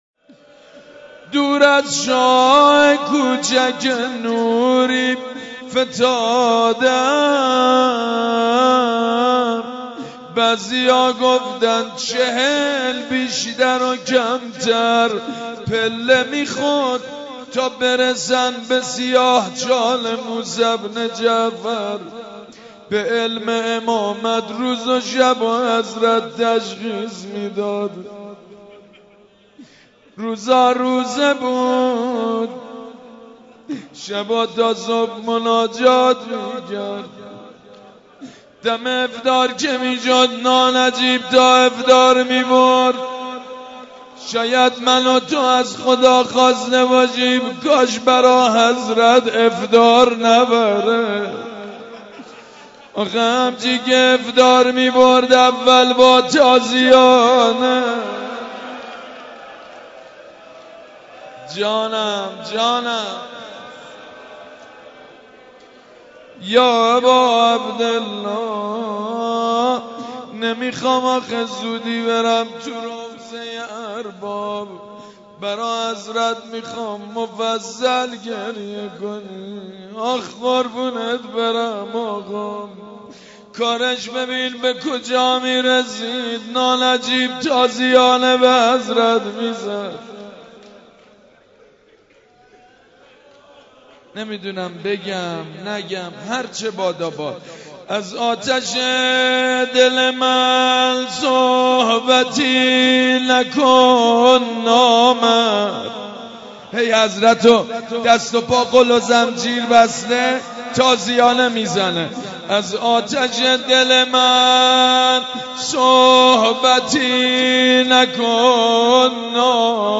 روضه امام موسی کاظم (ع) (روضه،سال 93)